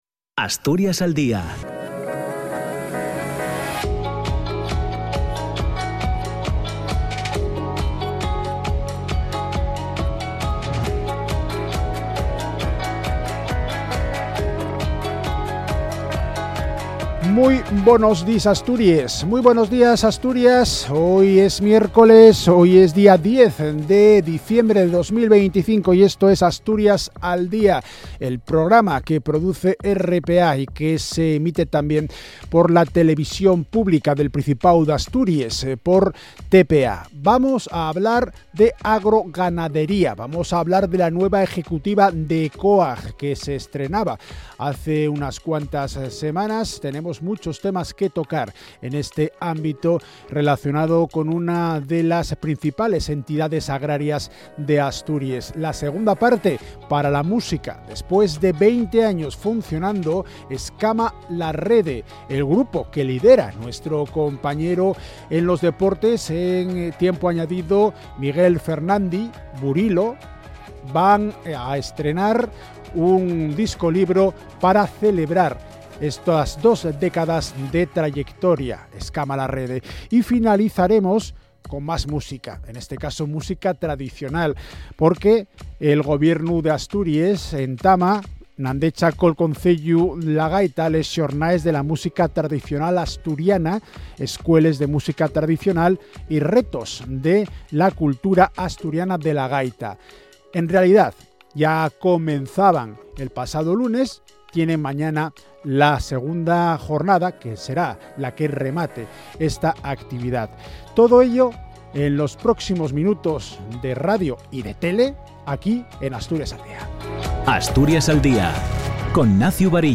1ª PARTE TERTULIA POLÍTICA SOBRE LAS CERCANÍAS FERROVIARIAS